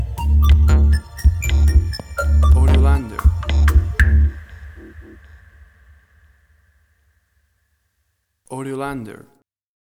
Tempo (BPM): 60